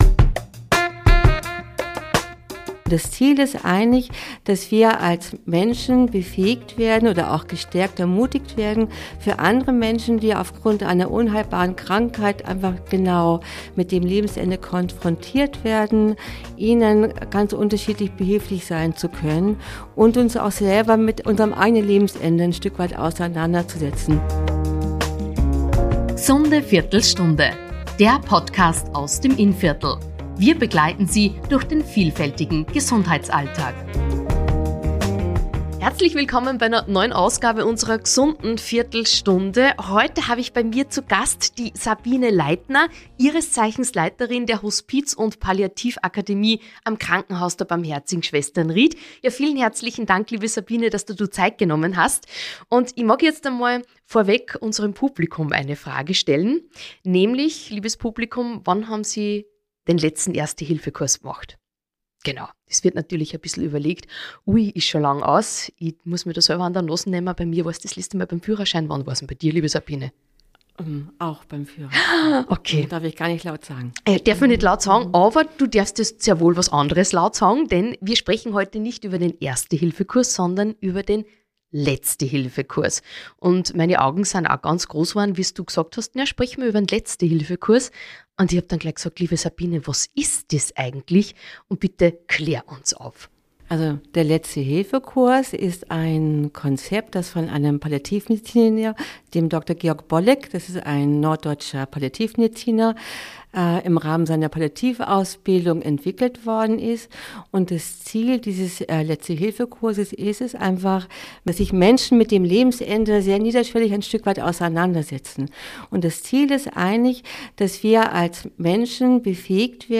Ein Gespräch über Mut, Menschlichkeit und die Kraft des Daseins – auch wenn Worte fehlen.